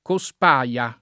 Cospaia [ ko S p #L a ]